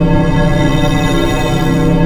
MERCURY TUBE.wav